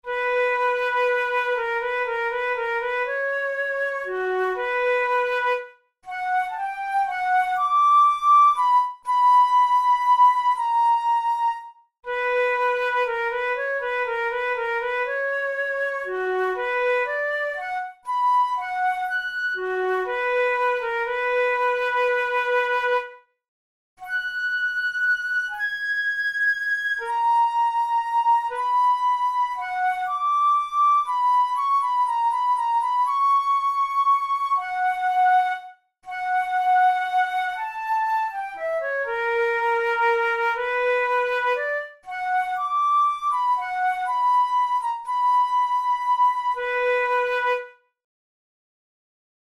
Categories: Etudes Written for Flute Difficulty: easy